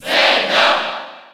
Category:Crowd cheers (SSB4) You cannot overwrite this file.
Zelda_Cheer_French_PAL_SSB4.ogg.mp3